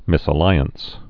(mĭsə-līəns)